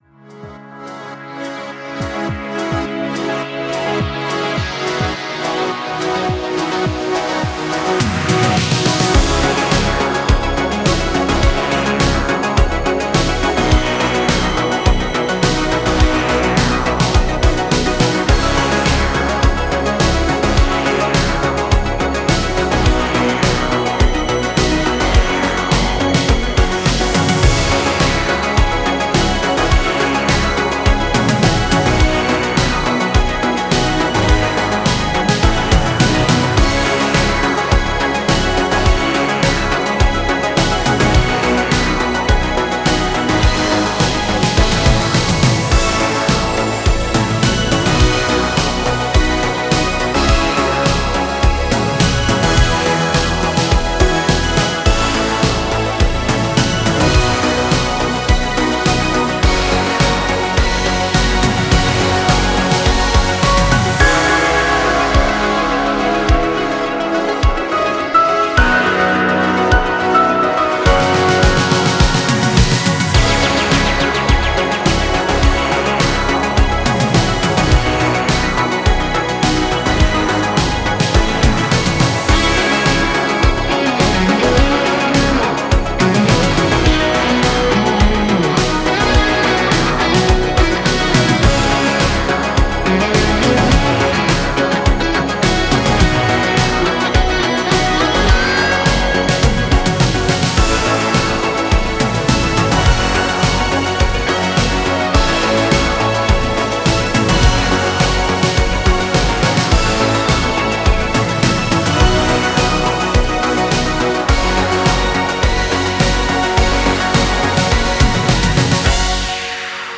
Retrowave-Back-To-The-80s.mp3